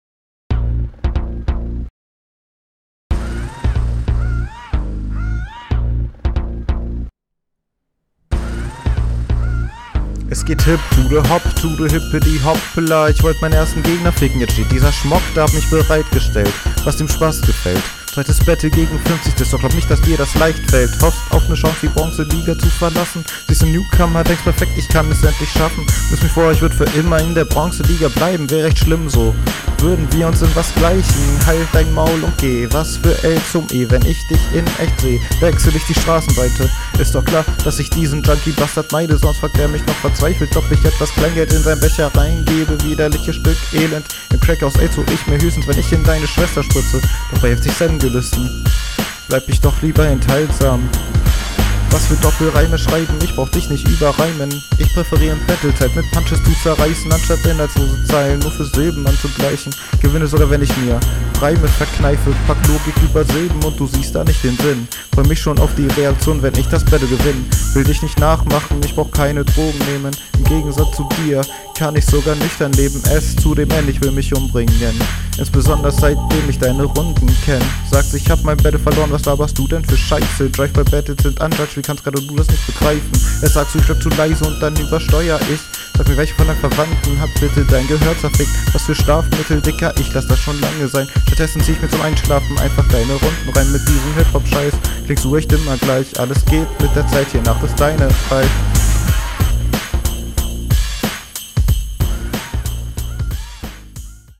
Flow ok beim Gegner stärker. Manchmal verhaspelst du dich leicht.
nicht so routiniert wie der gegner und du stolperst an paar stellen über den beat.
Du hast dich null abgemischt was hier deutlich hervorgeht. Flowvarriationen sind hier auch Fehlware.